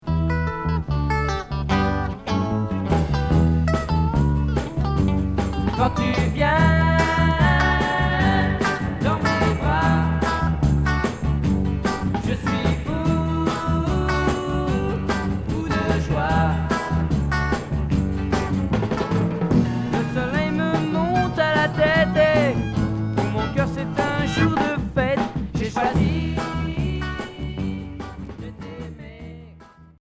Folk beat